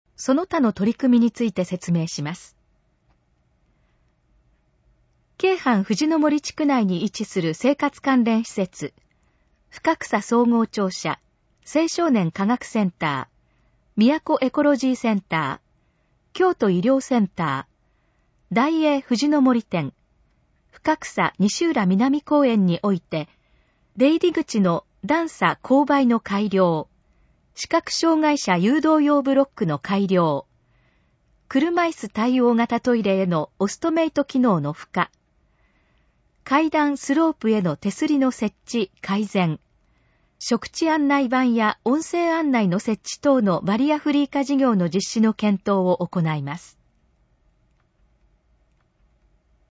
以下の項目の要約を音声で読み上げます。